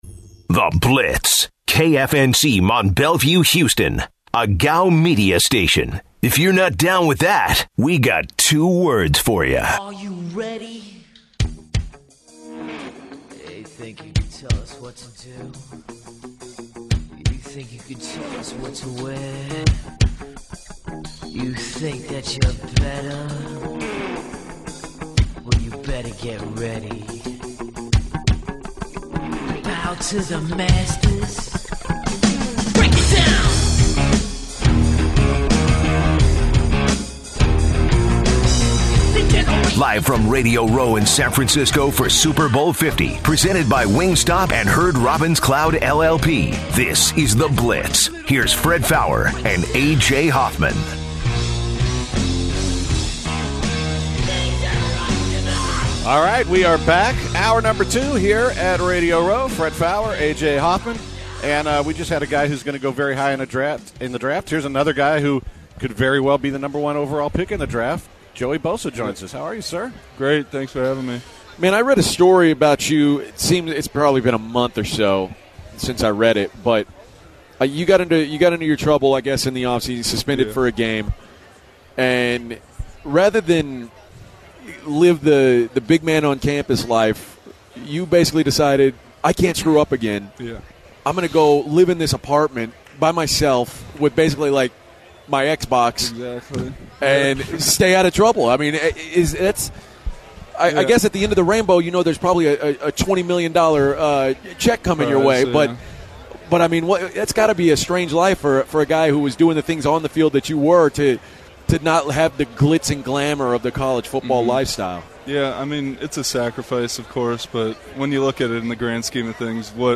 live from San Francisco. Ohio State Defensive End Joey Bosa joins the show to discuss his stock in the upcoming NFL Draft. Washington Redskins Safety Duke Ihenacho joins the Blitz to discuss the past season of the Washington Redskins and the growth of Kirk Cousins. Former Chicago Bears Linebacker Lance Briggs joins the Blitz to discuss his life after football and current state of the Chicago Bears.